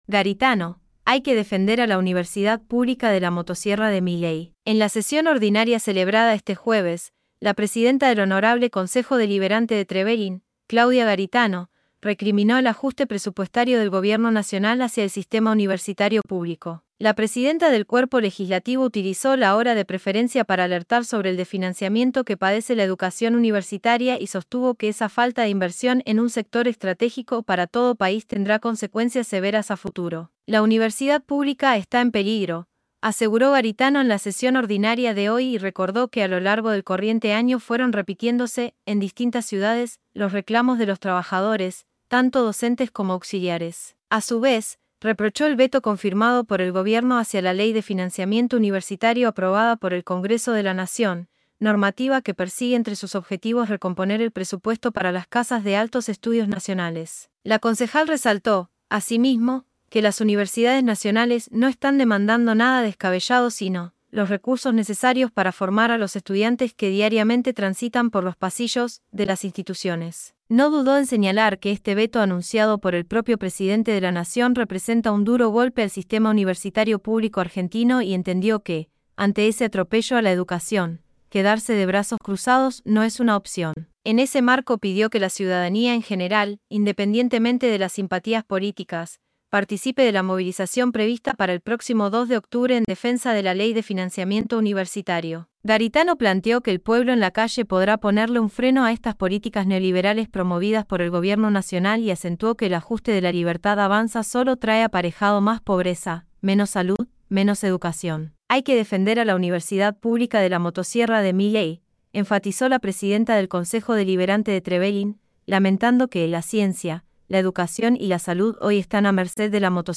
En la sesión ordinaria celebrada este jueves, la presidenta del Honorable Concejo Deliberante (HCD) de Trevelin, Claudia Garitano, recriminó el ajuste presupuestario del gobierno nacional hacia el sistema universitario público. La presidenta del cuerpo legislativo utilizó la Hora de Preferencia para alertar sobre el desfinanciamiento que padece la educación universitaria y sostuvo que esa falta de inversión en un sector estratégico para todo país tendrá consecuencias severas a futuro.
claudia_garitano_-_xii_sesion_ordinaria_2024.mp3